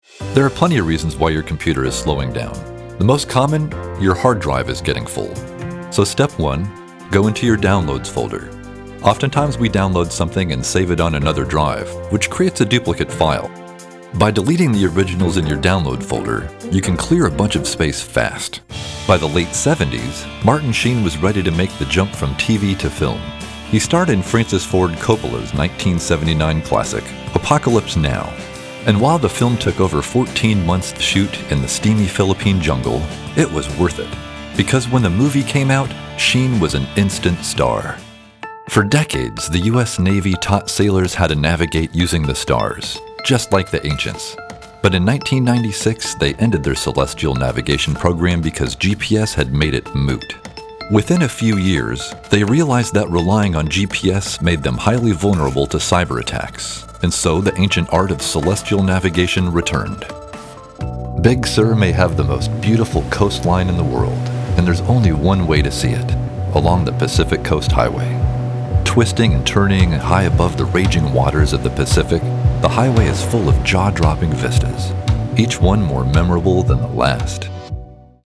Male Voiceover Talent